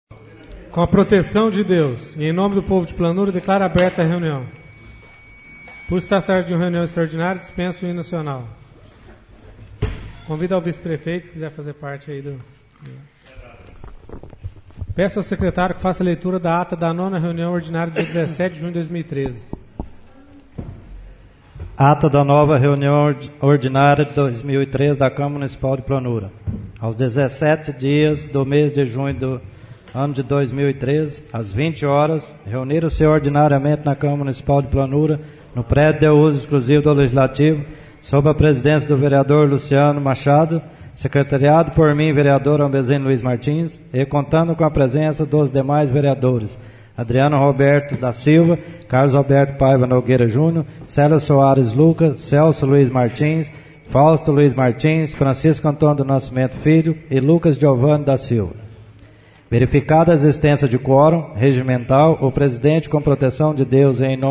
Sessão Extraordinária - 09/07/13